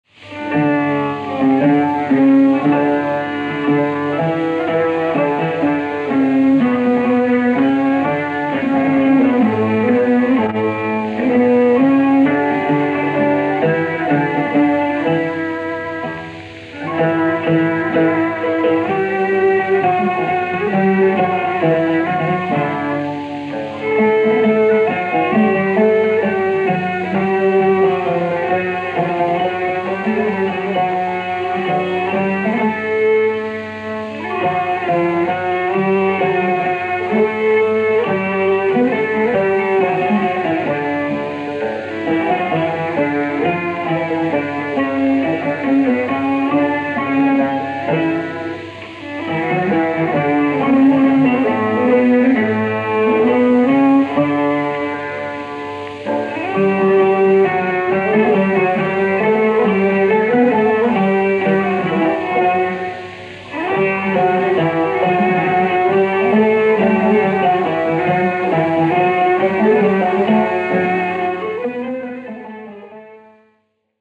Genre: Turkish & Ottoman Classical.
tanbur
violin
viola
kanun